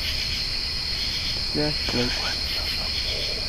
We began our investigation at approximately 11:30pm.  It was clear and mild with temperatures in the 50's.  We managed to capture some very chilling EVPs in which a couple sound like a spell is being chanted.